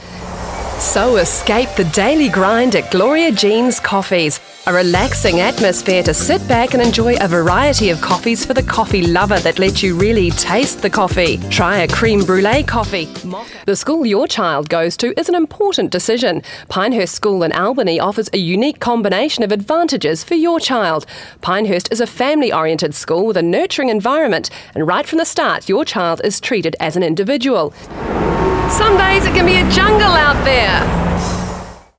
Warm familiar and trustworthy...
Demo
Location: Auckland, New Zealand Accents: new zealand | natural